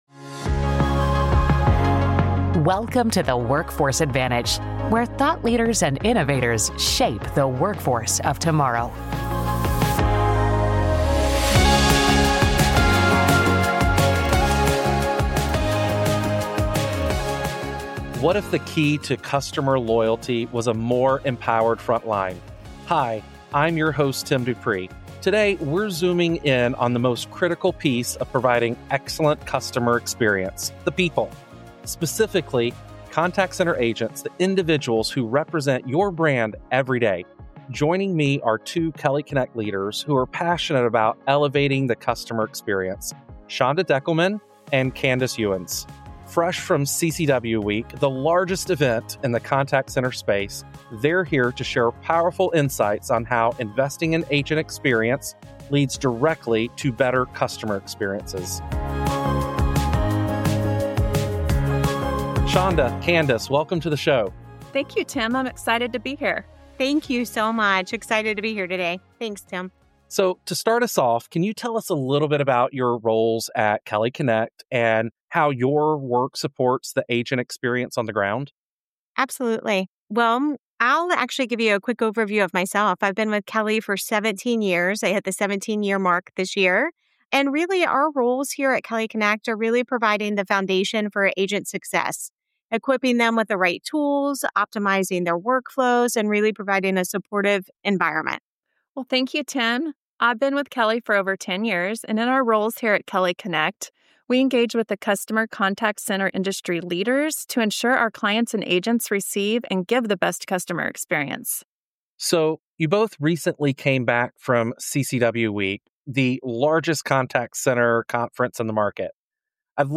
each episode features expert conversations with thought leaders and innovators at the forefront of workforce trends and solutions.